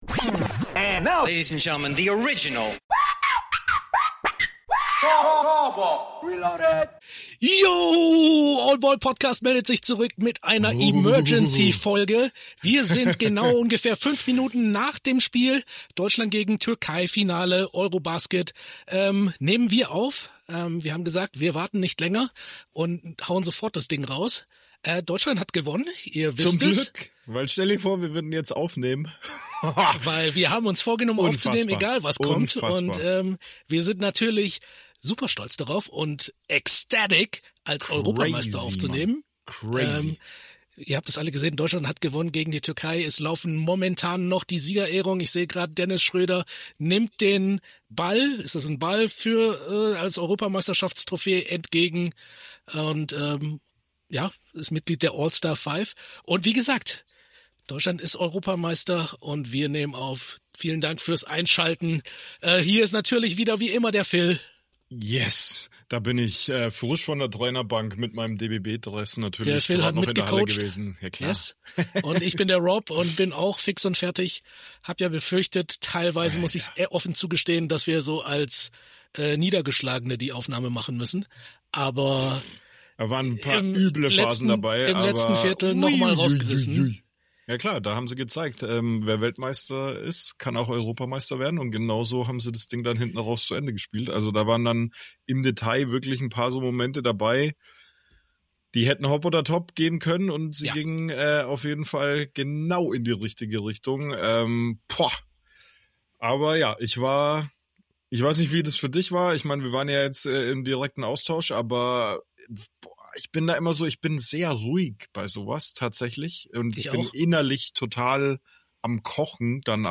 5 Minuten nach der Schlusssirene in Riga haben wir aufgenommen! Deutschland ist Europameister!!! Die Emotionen sind frisch und raw und wir sind noch ganz aus dem Häuschen und nervlich total am Ende.